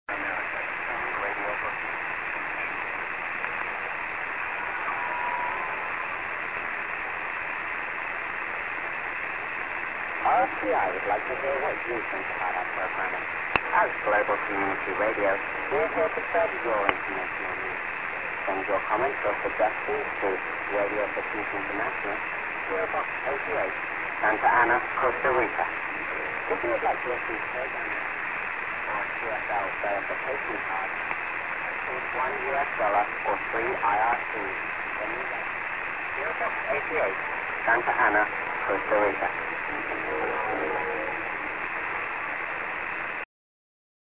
->ANN(man)->ADDR=RFPI->